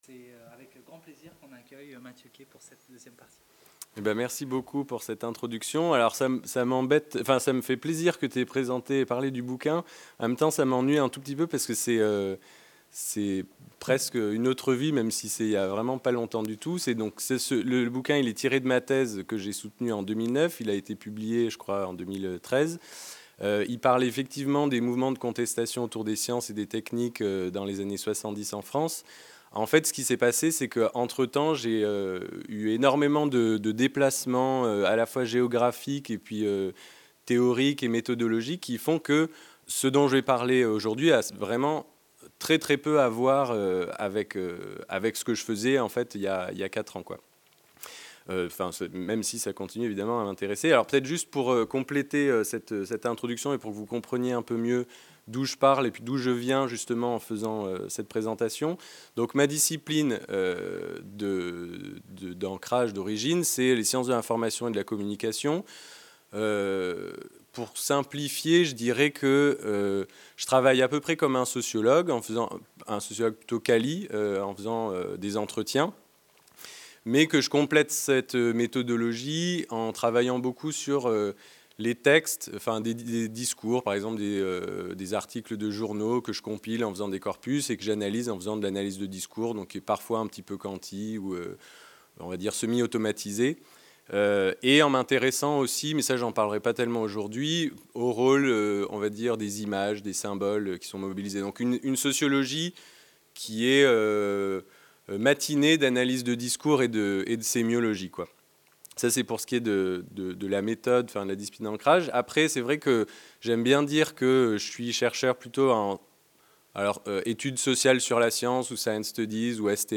Dispositifs de lutte contre les médicaments illicites et production de flux pharmaceutiques Séminaire interdisciplinaire organisé à l’Institut Français de l’Éducation de l’École Normale Supérieure de Lyon.